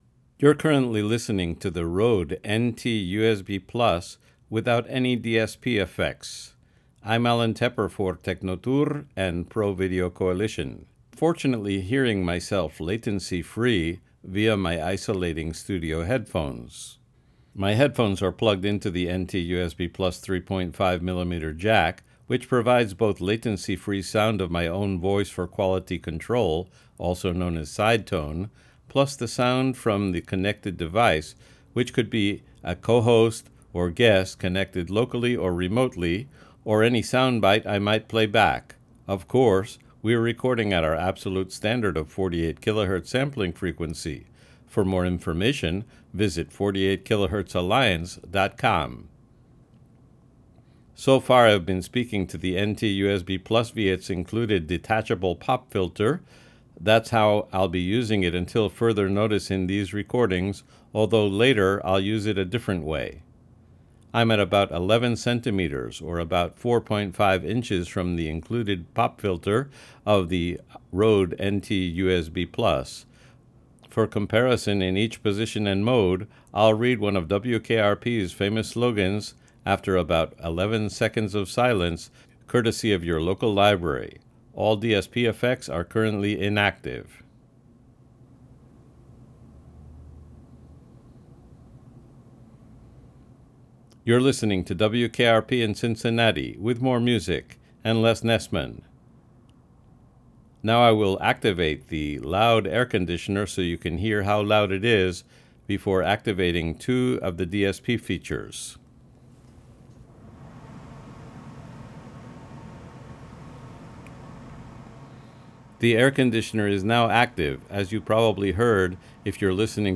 Test recordings, raw
The following recording goes from no DSP effect to each one:
Especially if you listen with headphones, you will indeed hear some of the room and reverb, especially before the activation of the noise gate.
It was probably because of the type of noise I used (a loud air conditioner).